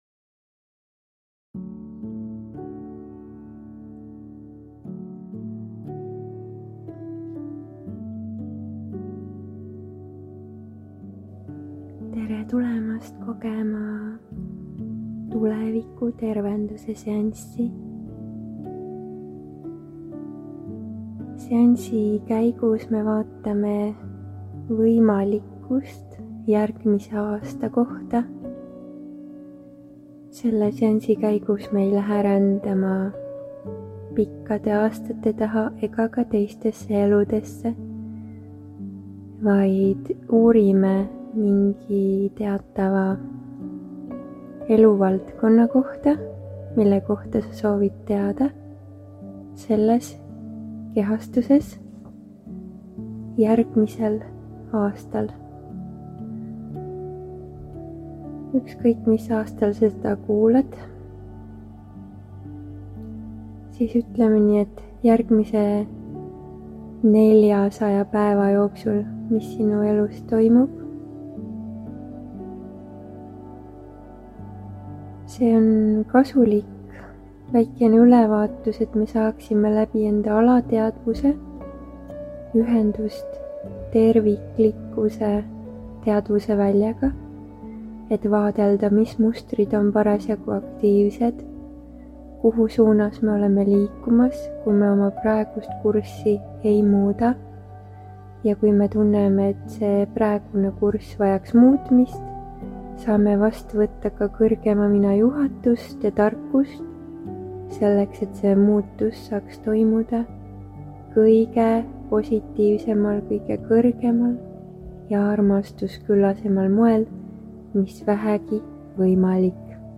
Siit lehelt saad kuulata ja alla laadida järgmist faili: MEDITATSIOON TULEVIKU TERVENDUS vaatle oma tuleviku-võimalusi u aasta jooksul – mis on võimalik?